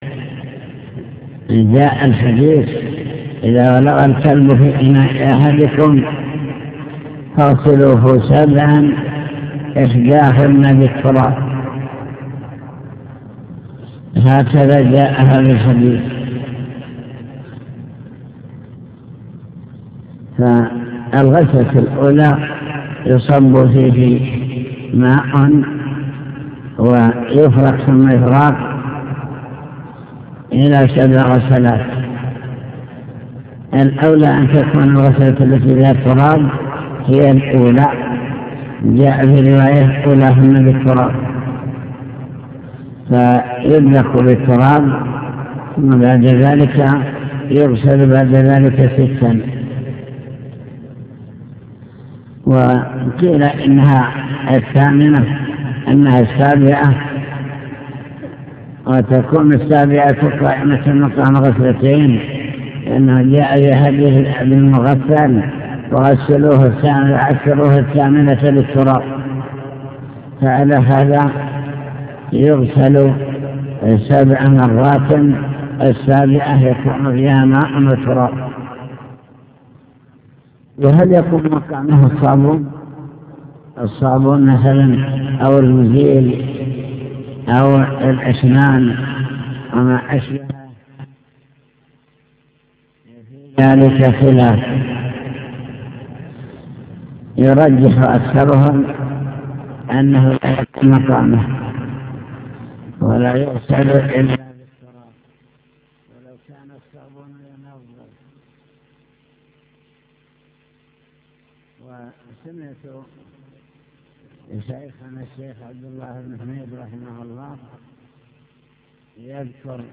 المكتبة الصوتية  تسجيلات - كتب  شرح كتاب دليل الطالب لنيل المطالب كتاب الطهارة باب إزالة النجاسة